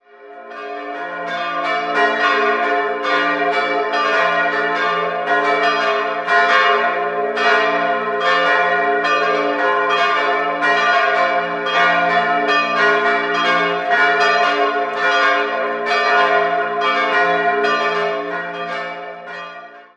4-stimmiges Salve-Regina-Geläute: f'-a'-c''-d''
bell
Das Geläute erklingt offiziell im Salve-Regina-Motiv, die Schlagtonlinie wird jedoch nicht genau getroffen.